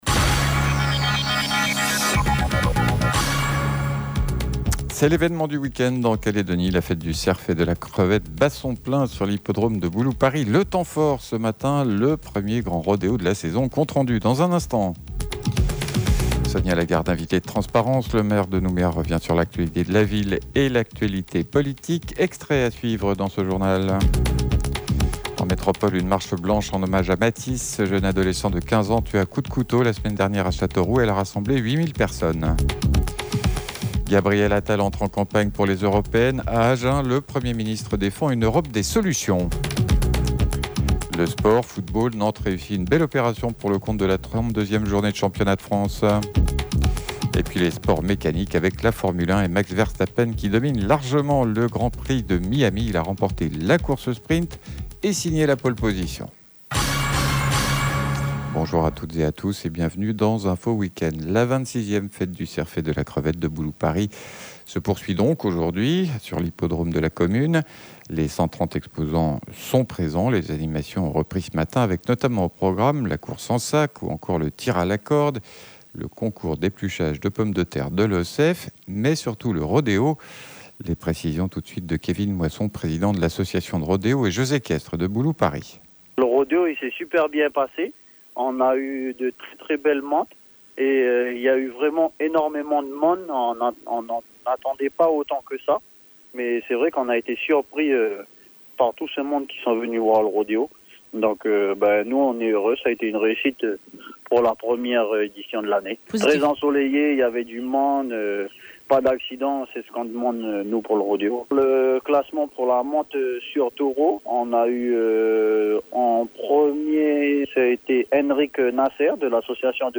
JOURNAL : INFO WEEK END DIMANCHE MIDI